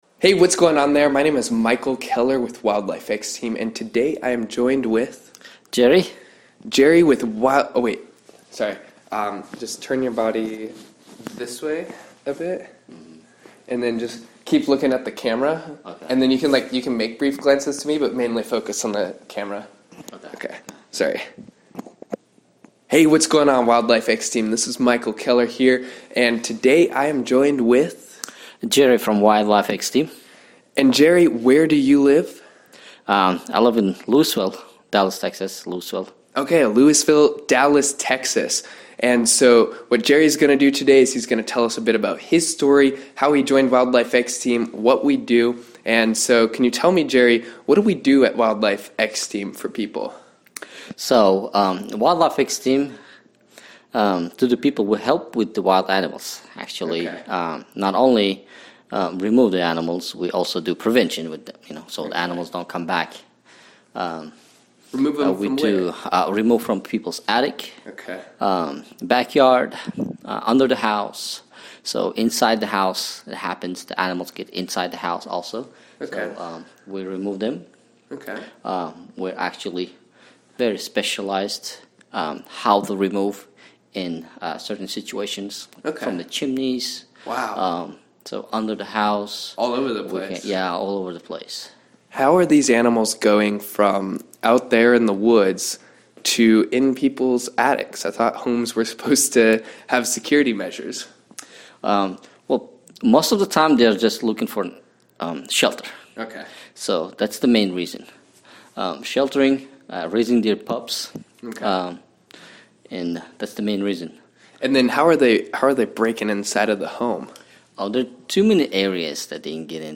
In this fascinating interview, we cover some interesting topics, such as: